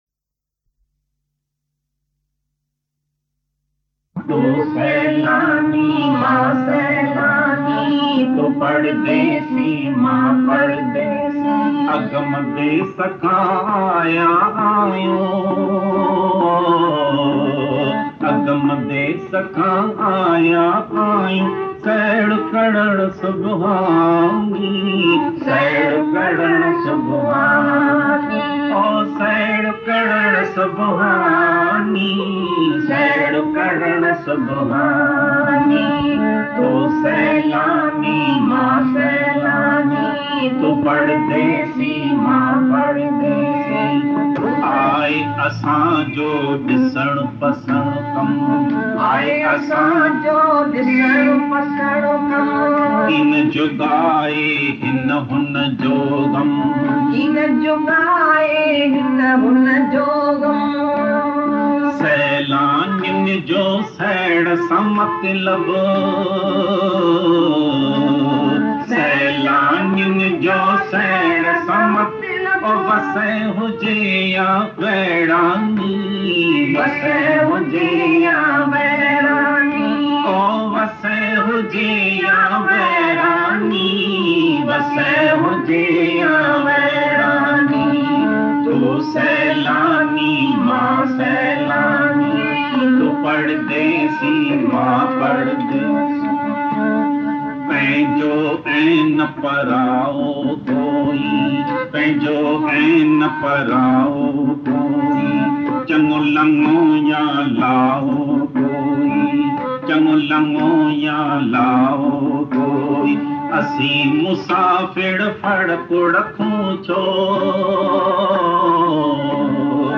Tu Sailani Maa Sailani Bhajan | तू सैलानी मां सैलानी भजनDivine Geeta Bhagwan Hindi Bhajans